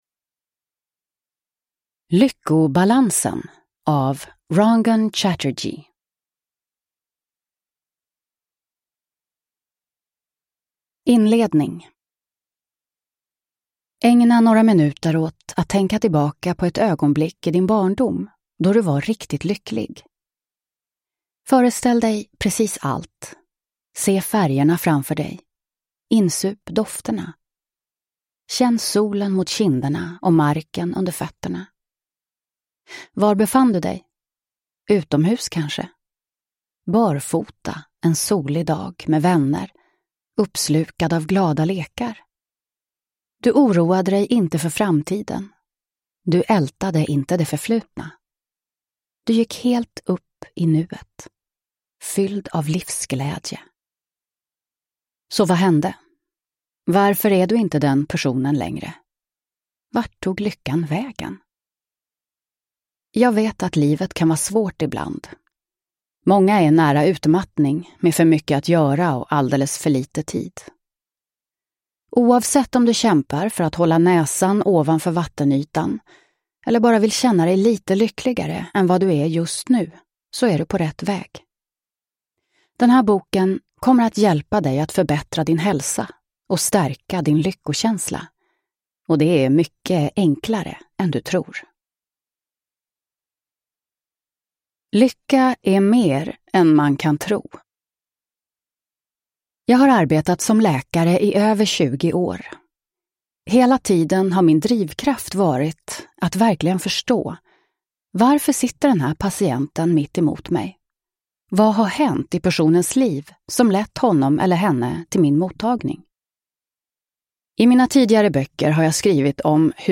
Lyckobalansen : 10 enkla sätt att må bra varje dag – Ljudbok – Laddas ner